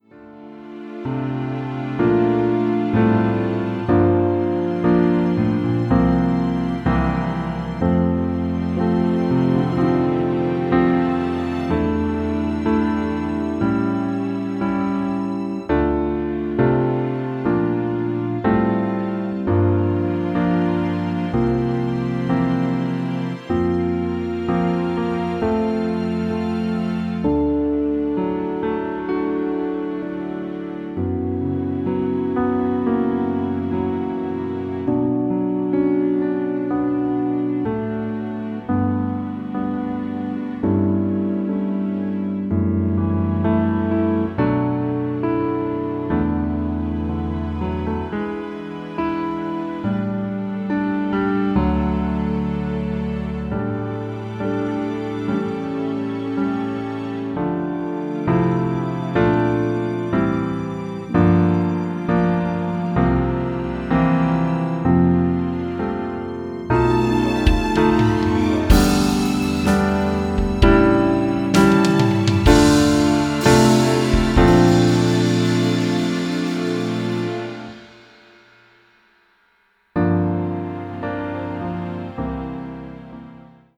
No guitar solo.
Key of F
(Transposed down 1/2 step)
Backing track only.